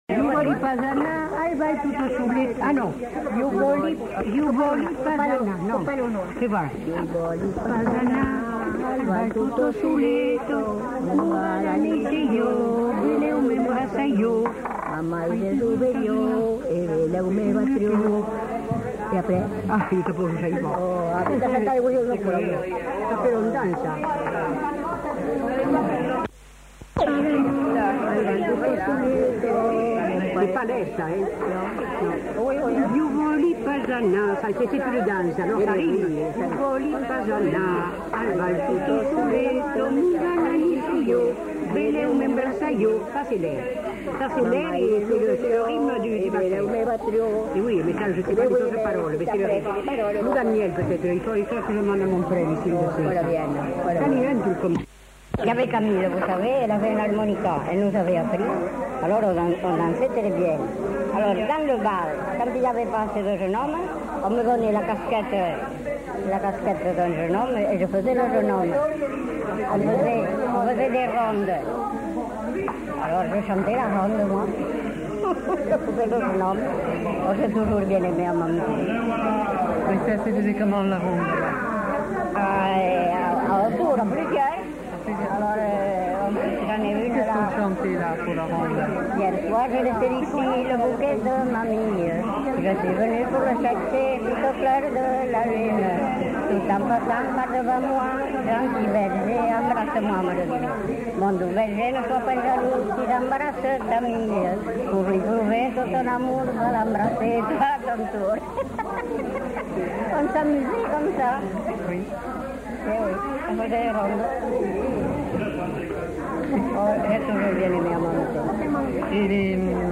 Aire culturelle : Haut-Agenais
Lieu : Cancon
Genre : chant
Effectif : 1
Type de voix : voix de femme
Production du son : chanté
Danse : quadrille
Notes consultables : En fin de séquence, évocation de la ronde avec bribes de chant.